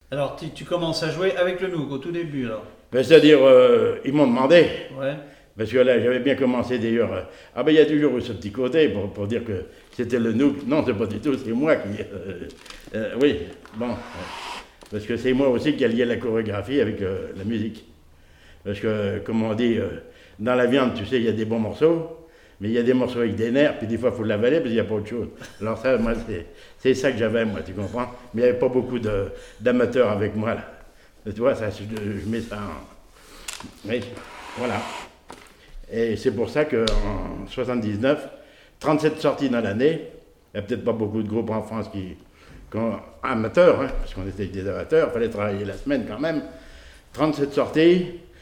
accordéon(s), accordéoniste
Témoignage sur la musique et des airs issus du Nouc'h
Catégorie Témoignage